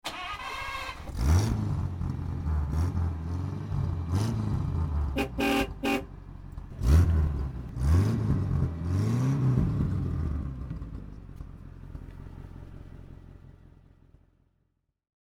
Plutôt que de nous appuyer sur des banques sonores formatées, nous privilégions la capture directe des sons sur le terrain.
Son – Pontiac de 1951